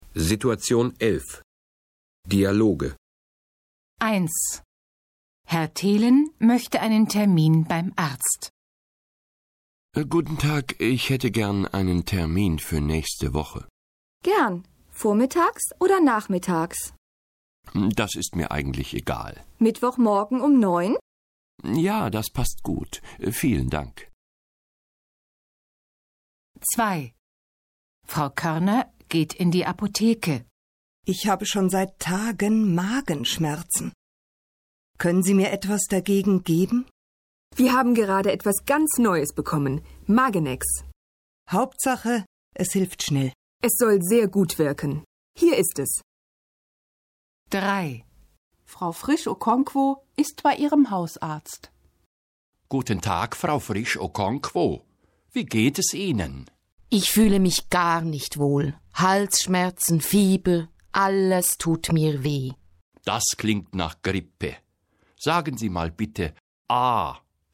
Situation 11 – Dialoge (1080.0K)